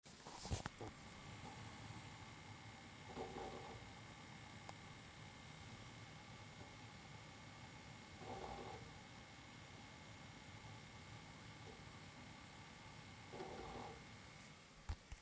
Seagate IronWolf Pro 4TB lautes kratzen noch normal?
Hallo zusammen, habe mir vor kurzen eine neue Seagate IronWolf Pro auf Amazon bestellt aber musste jetzt feststellen das diese doch sehr laute Kratz /schleif Geräusche von sich gibt die ich so bisher noch von keiner anderen gehört habe. Das ganze tritt beim Lesen und Schreiben im 3-4 Sekunden Takt auf.